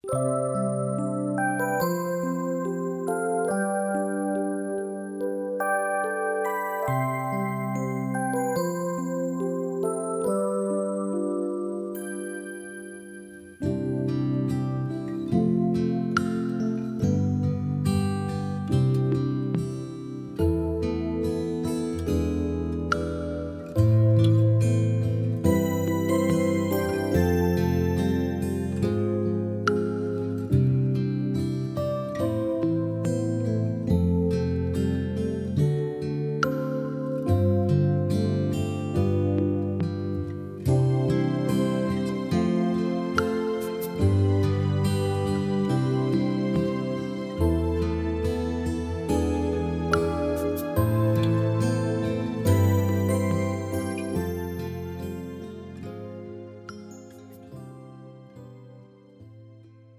장르 가요 구분 Premium MR